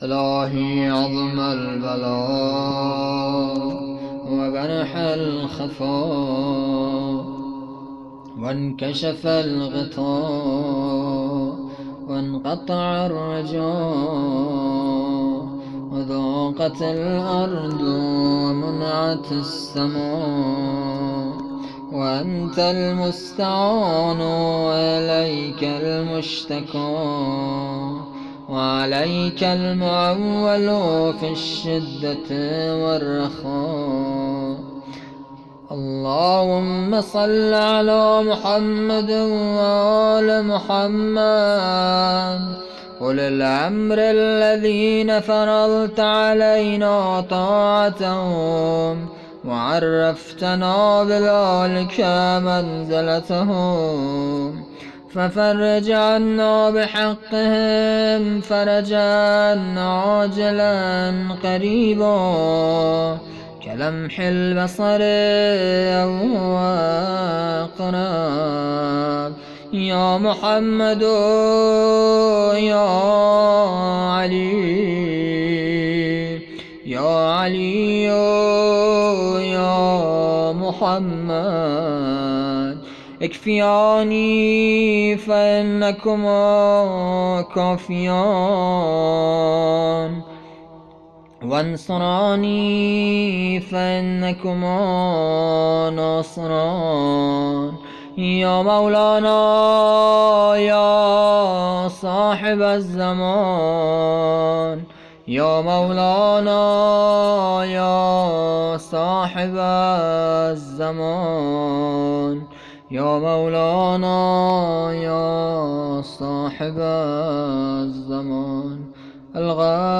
دعا